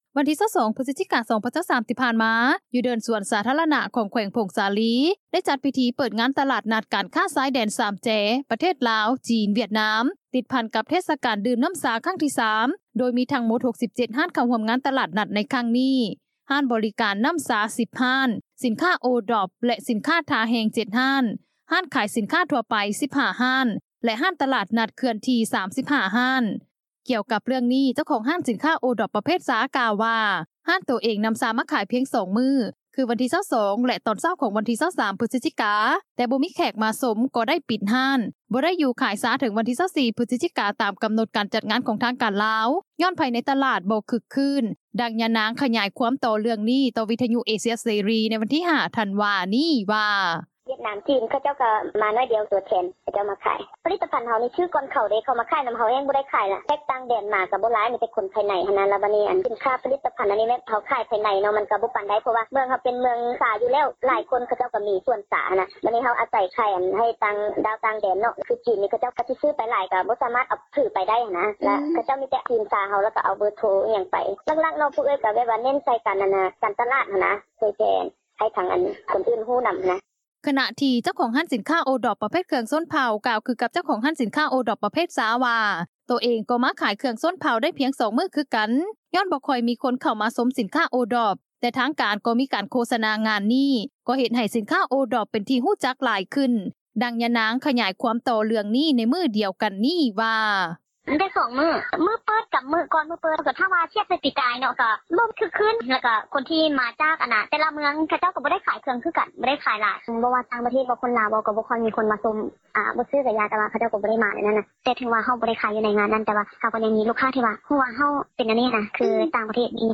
ກ່ຽວກັບເຣື່ອງນີ້ ເຈົ້າຂອງຮ້ານສິນຄ້າໂອດ໋ອບ ປະເພດຊາ ກ່າວວ່າ ຮ້ານໂຕເອງ ນໍາຊາມາຂາຍ ພຽງ 2 ມື້ ຄືວັນທີ 22 ແລະຕອນເຊົ້າ ຂອງວັນທີ 23 ພຶສຈິກາ ແຕ່ບໍ່ມີແຂກມາຊົມ ກໍໄດ້ປິດຮ້ານ ບໍ່ໄດ້ຢູ່ຂາຍຊາເຖິງວັນທີ 24 ພຶສຈິກາ ຕາມກໍານົດຈັດງານ ຂອງທາງການລາວ ຍ້ອນພາຍໃນຕລາດບໍ່ຄຶກຄື້ນ, ດັ່ງຍານາງ ຂຍາຍຄວາມຕໍ່ເຣື່ອງນີ້ ຕໍ່ວິທຍຸເອເຊັຽເສຣີ ໃນວັນທີ 5 ທັນວາ ນີ້ວ່າ